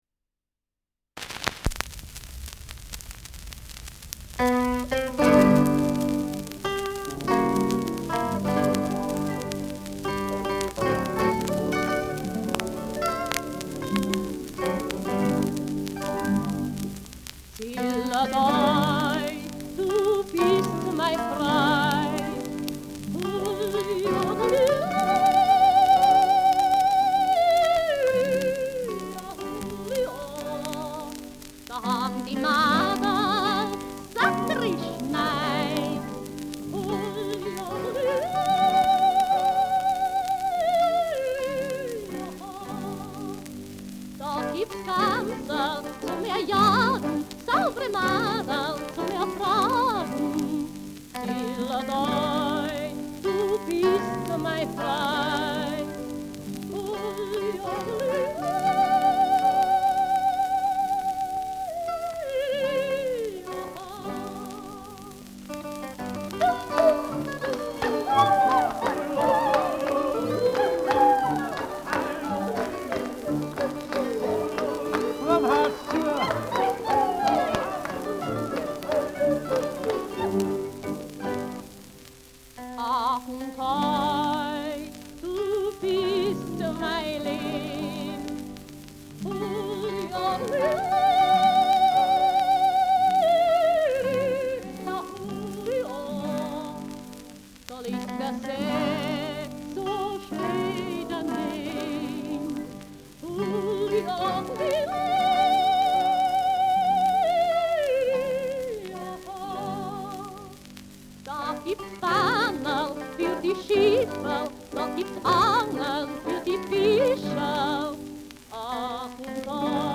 Schellackplatte
Leicht abgespielt : Erhöhtes Grundrauschen : Häufiges Knacken
[Berlin] (Aufnahmeort)
Stubenmusik* FVS-00016